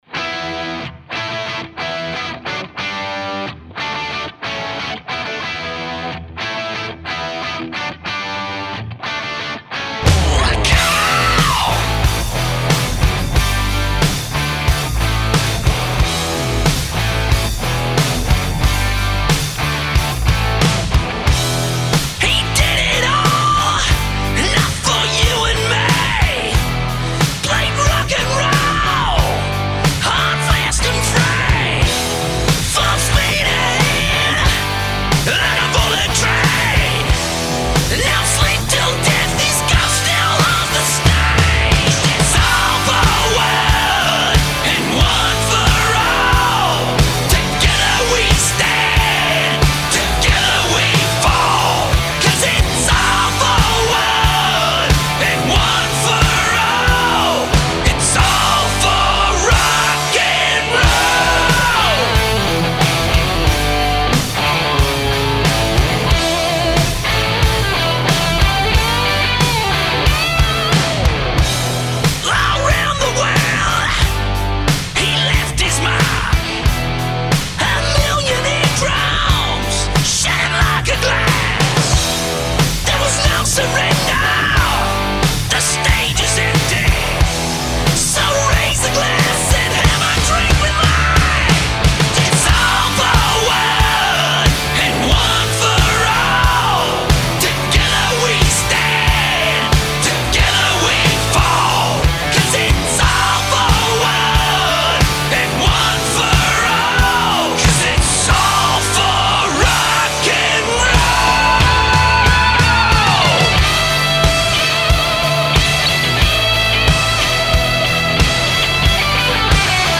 Airbourne Interview